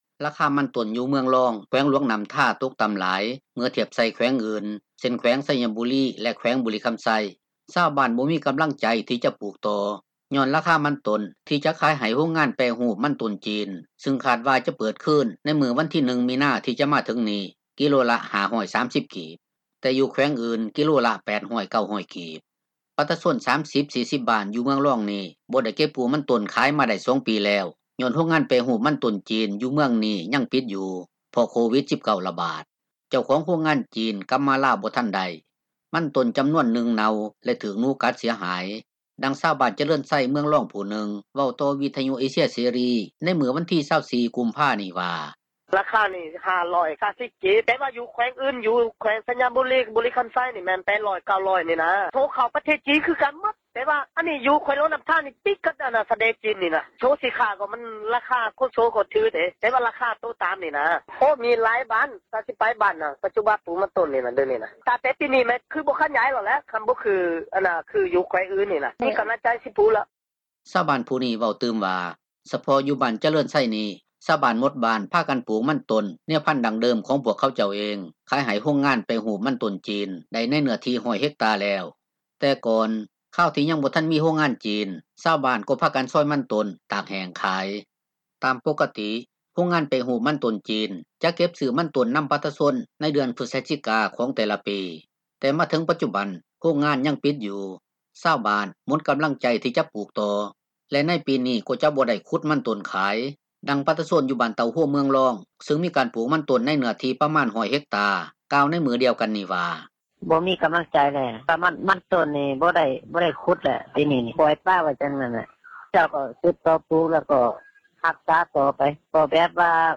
ດັ່ງຊາວ ບ້ານຈະເຣີນໄຊ ເມືອງລອງຜູ້ນຶ່ງ ເວົ້າຕໍ່ວິທຍຸເອເຊັຽເສຣີໃນ ມື້ວັນທີ 24 ກຸມພານີ້ວ່າ:
ດັ່ງປະຊາຊົນຢູ່ບ້ານສົມປານໃຕ້ ເມືອງລອງ ເວົ້າຕໍ່ວິທຍຸເອເຊັຽເສຣີໃນມື້ວັນທີ 24 ກຸມພານີ້ວ່າ: